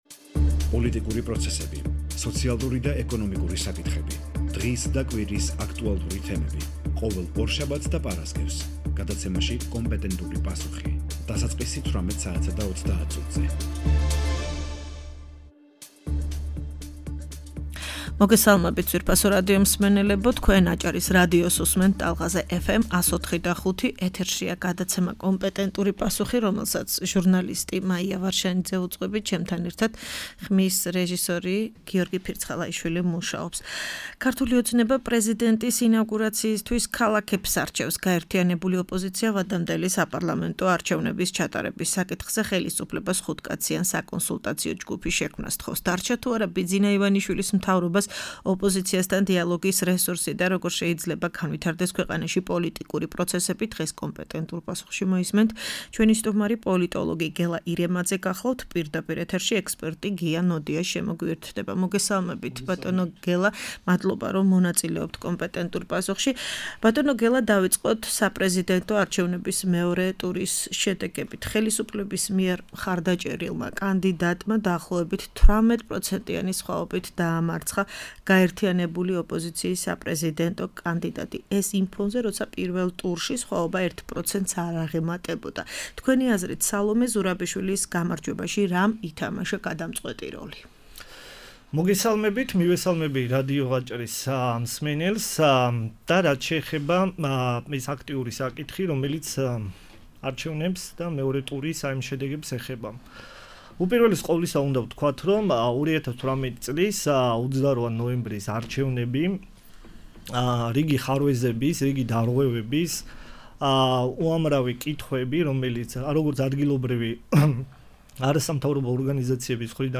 პირდაპირ ეთერში ექსპერტი გია ნოდია ჩაერთო.